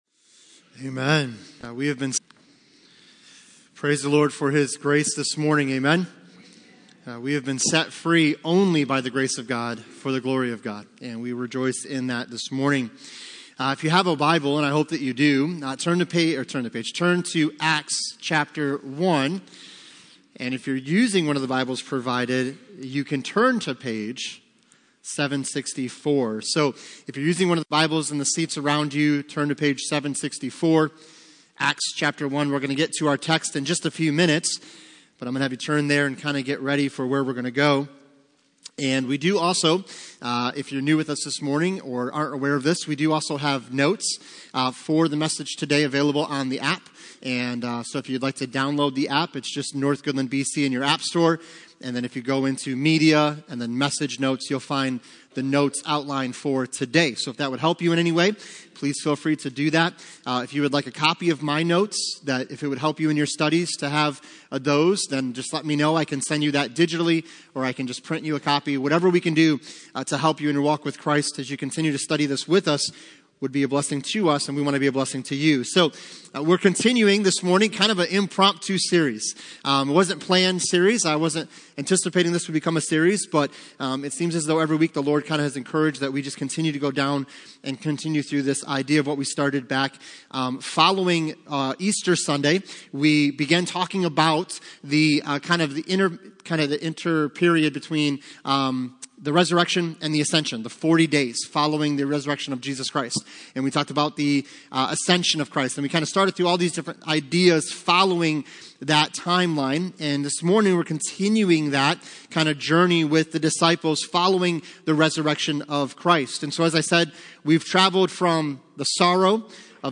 Acts 1 Service Type: Sunday Morning CCLI Streaming Plus License